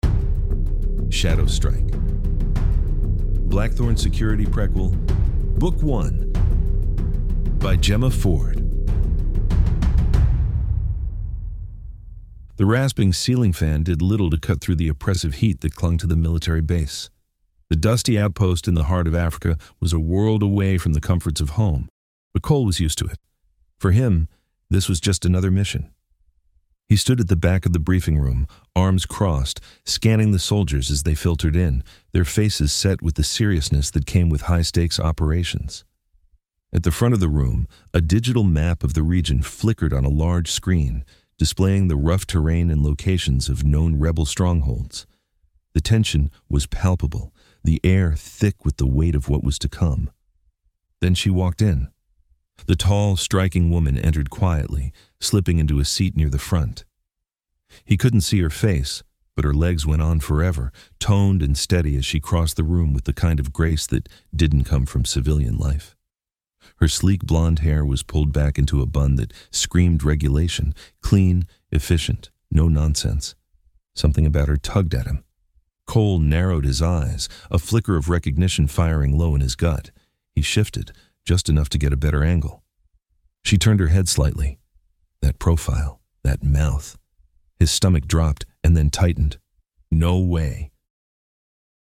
Shadow-Strike-intro-chapter-audiobook-1.mp3